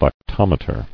[lac·tom·e·ter]